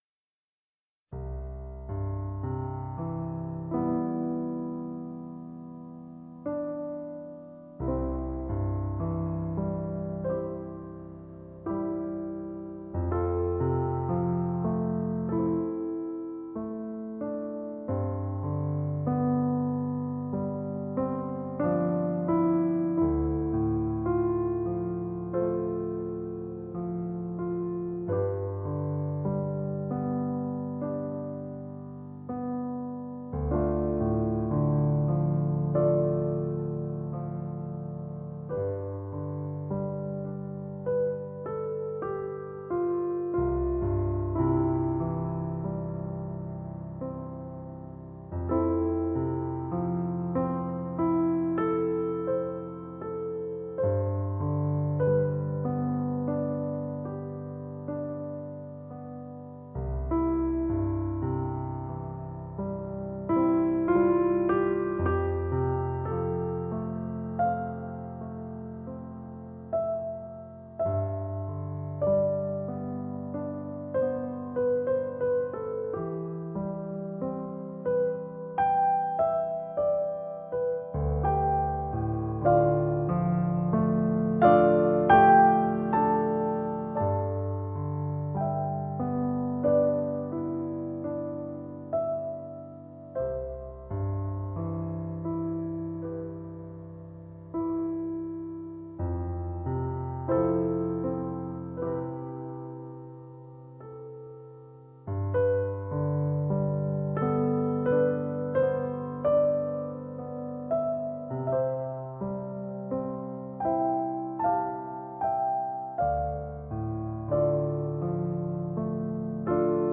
2 piano pieces at 25 minutes each.